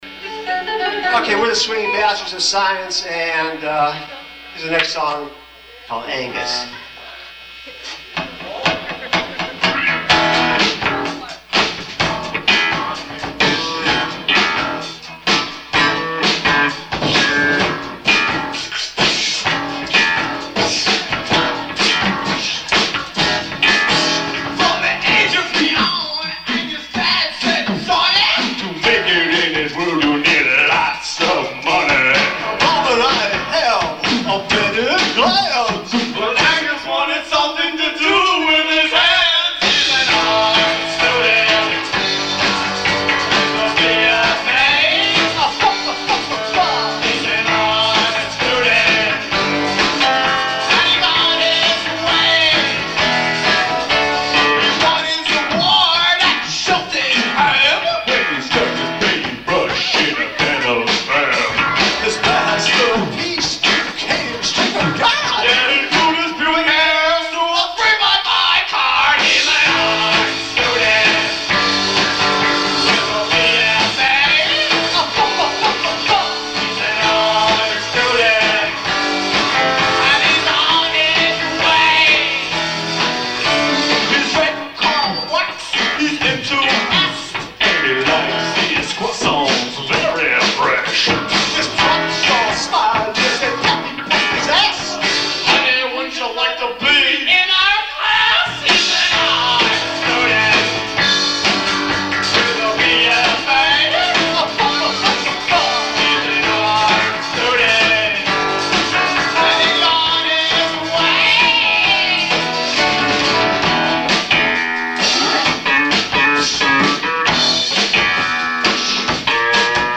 vocals
Guitar & Vocals
Bass
Drums.
Chestnut Cabaret, Philadelphia 1987